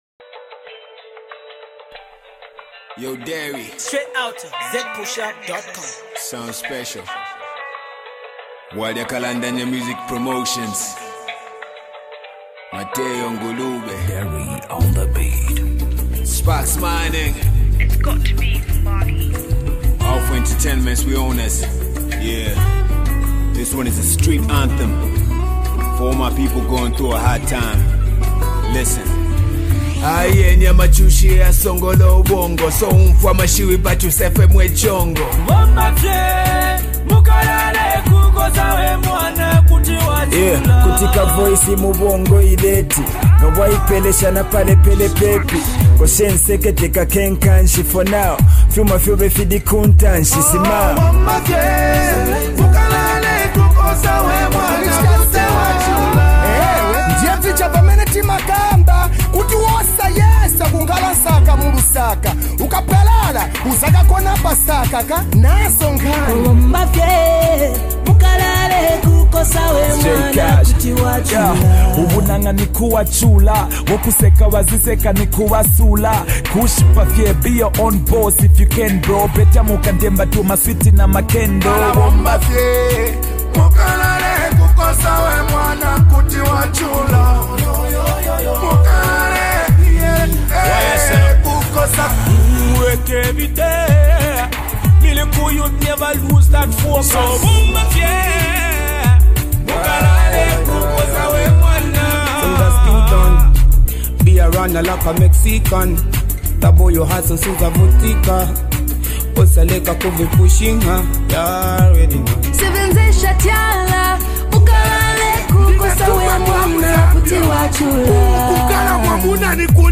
Motivational song